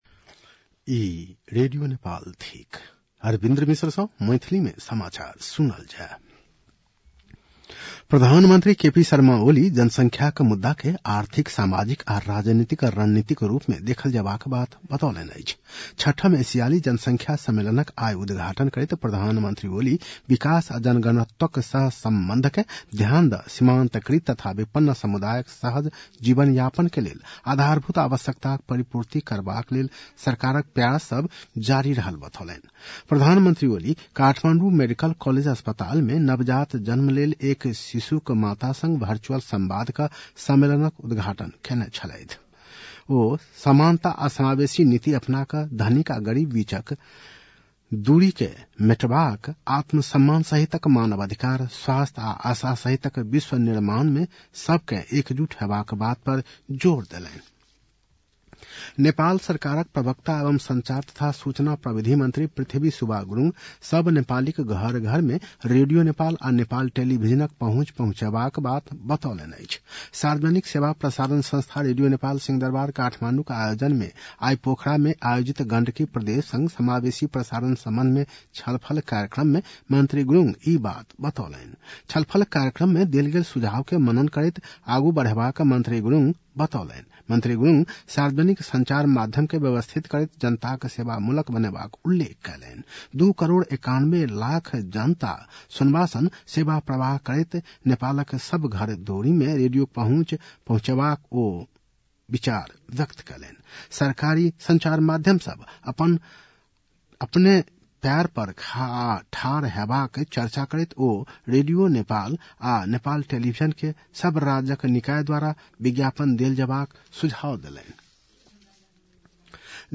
मैथिली भाषामा समाचार : १३ मंसिर , २०८१
Maithali-news-8-12.mp3